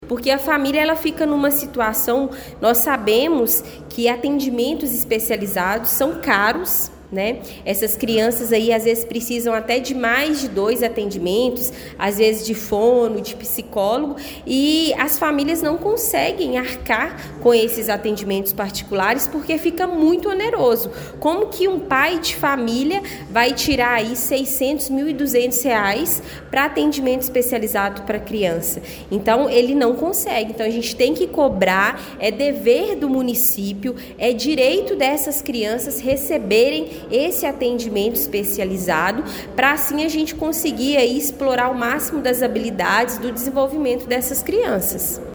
Durante a reunião da Câmara Municipal, realizada ontem, 2 de dezembro, a vereadora Camila Gonçalves de Araújo fez um pronunciamento contundente sobre a longa espera enfrentada por crianças que necessitam de atendimento com fonoaudiólogos e terapeutas ocupacionais.